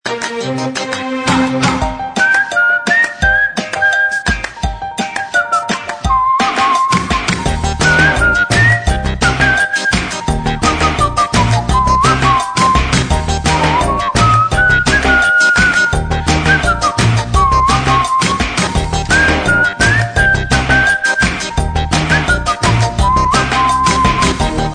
Gepfiffenes Lied… Wie heißt das Lied bzw. der Interpret?
pfeifton.mp3